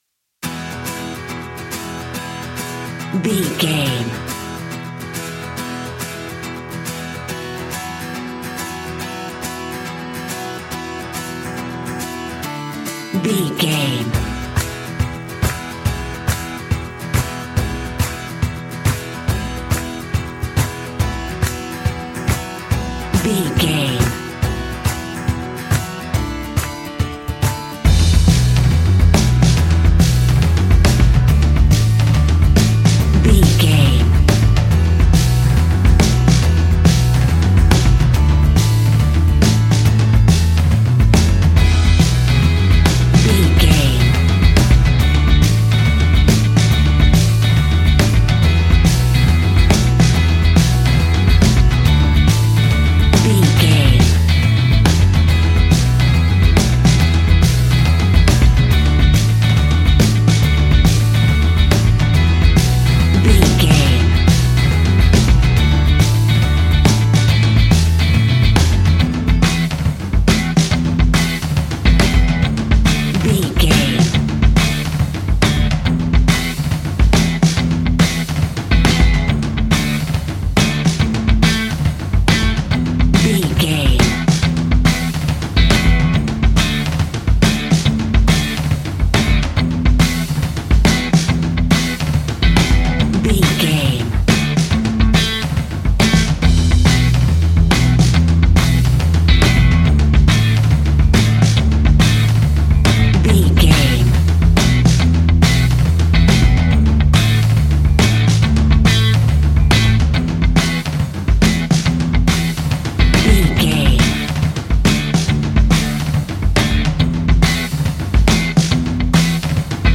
Ionian/Major
folk instrumentals
acoustic guitar
mandolin
ukulele
lapsteel
drums
double bass
accordion